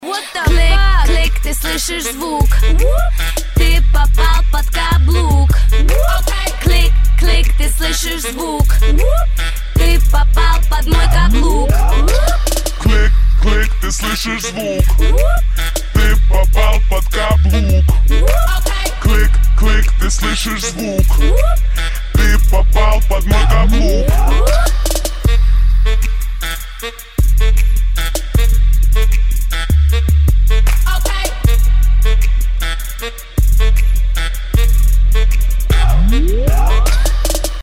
• Качество: 192, Stereo
веселые
смешные